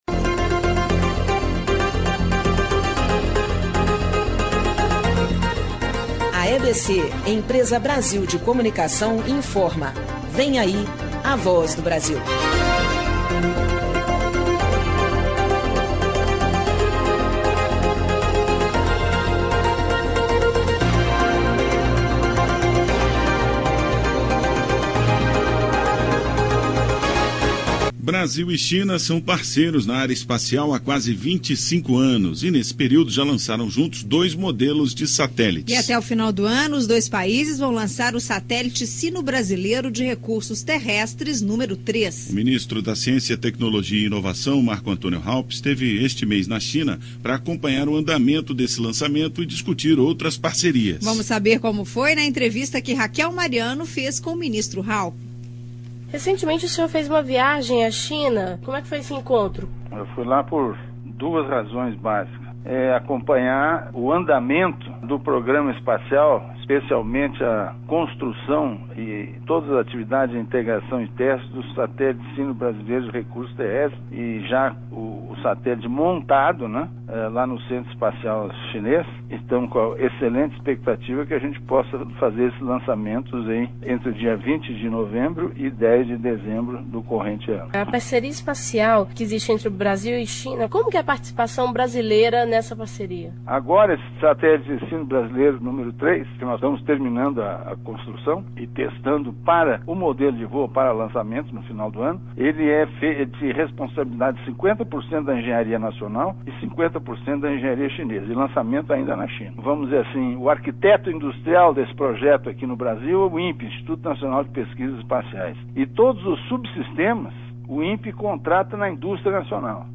Clique aqui para baixar o arquivo Entrevista